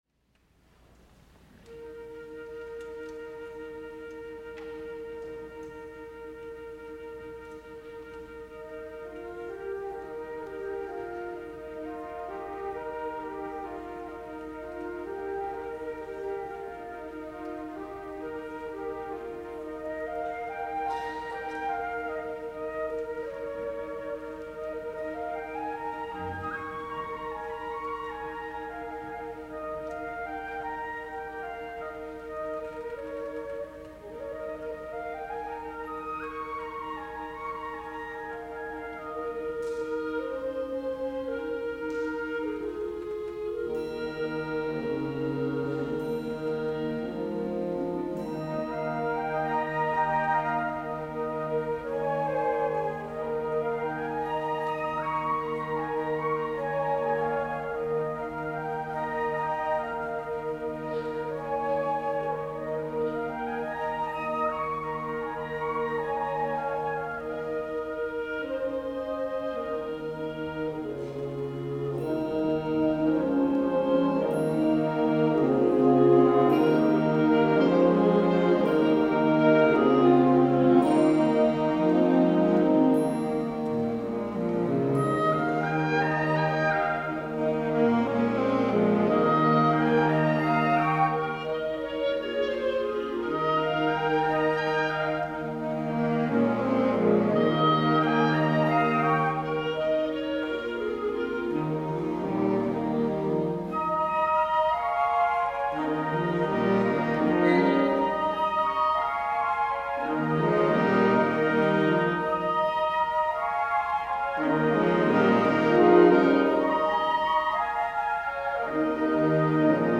for Band (1989)